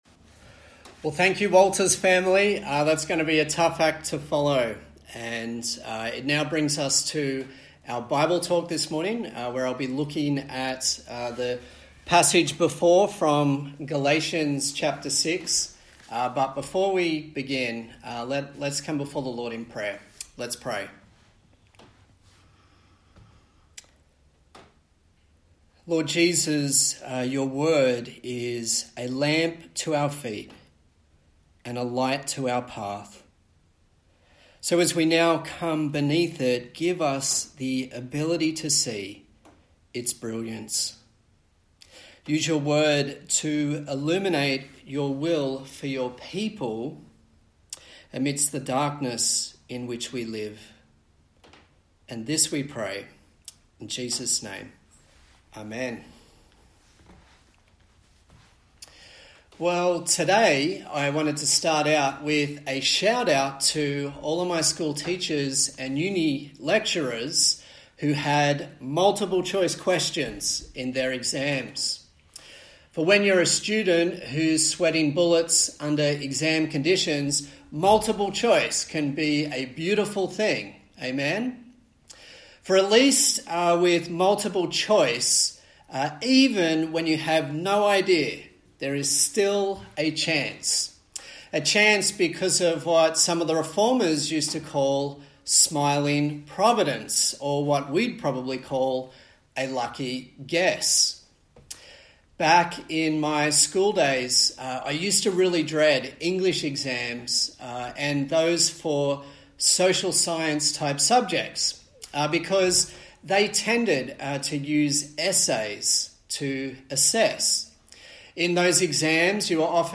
Galatians Passage: Galatians 6:11-18 Service Type: Sunday Morning